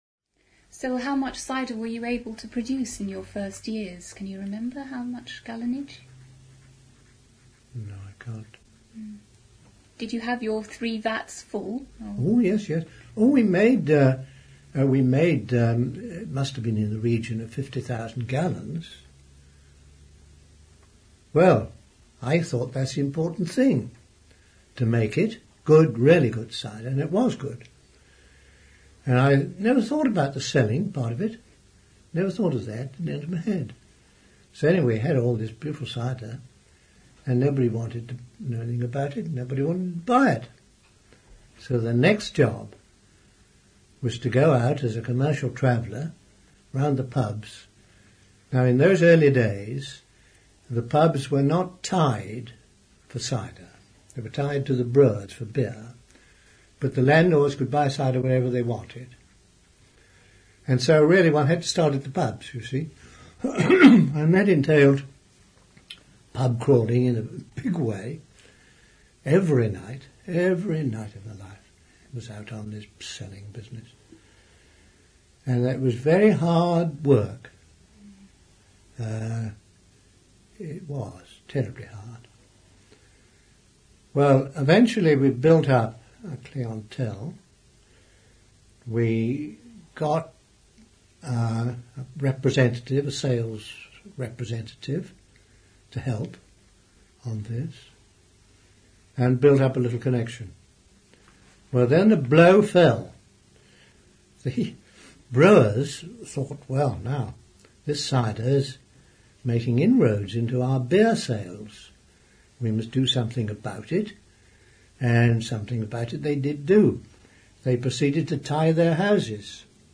For access to full interview please contact the Somerset Heritage Centre.